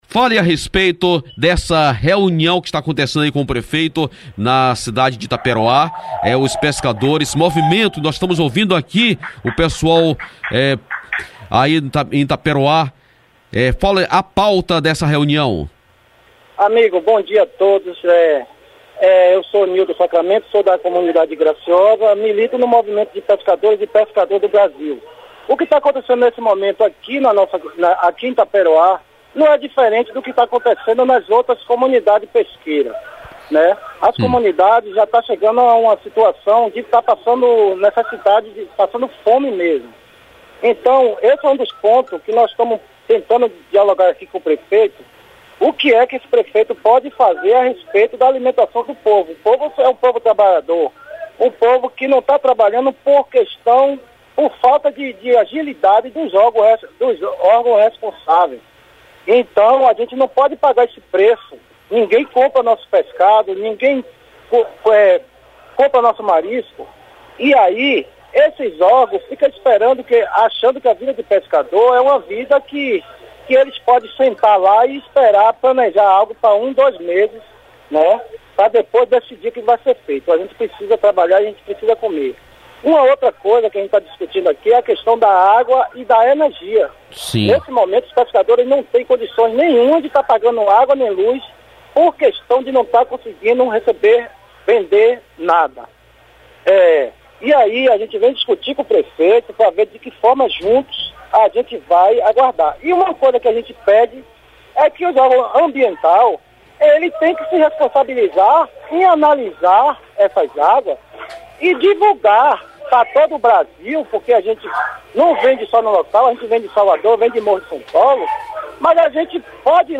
A entrevista foi veículada na Rádio Clube de Valença.